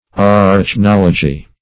Search Result for " arachnology" : The Collaborative International Dictionary of English v.0.48: Arachnology \Ar`ach*nol"o*gy\, n. [Gr.